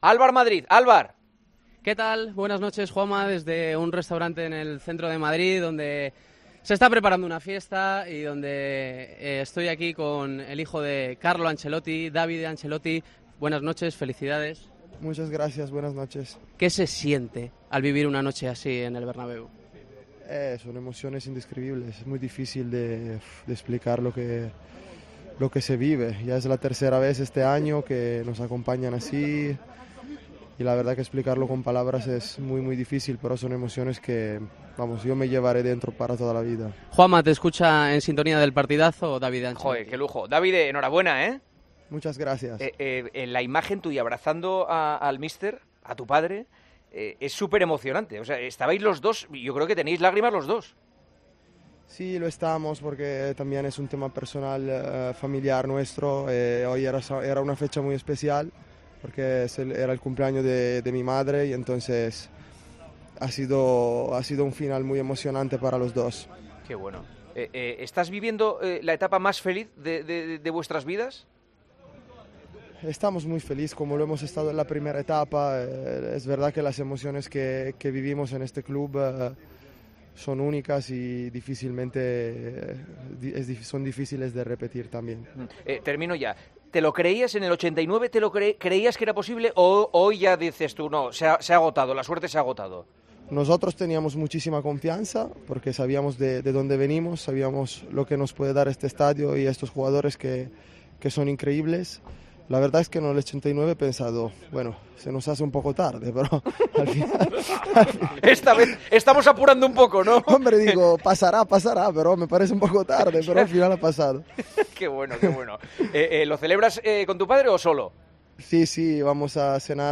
Hablamos con el hijo de Carlo Ancelotti, segundo entrenador del Real Madrid, después de la gran clasificación de los blancos para la final de la Champions League.